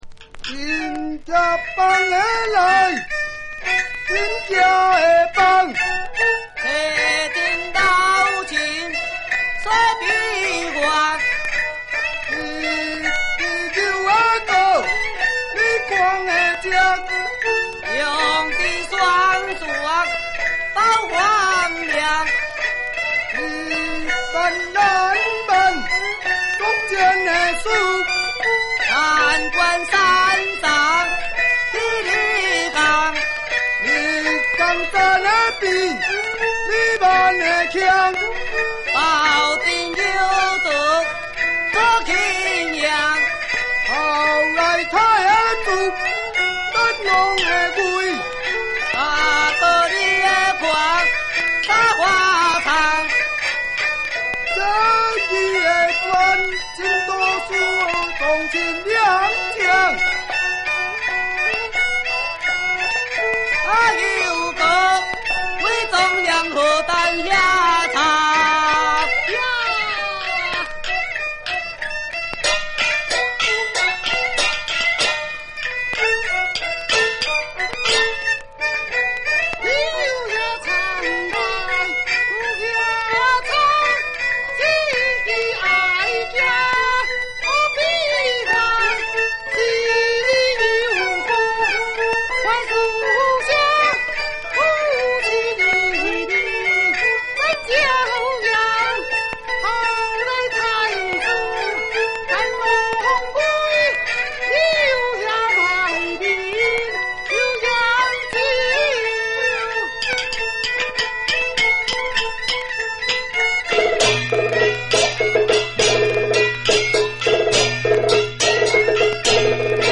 三進宮【西皮跺子】 北管新路戲曲 梨春園早期錄音資料計畫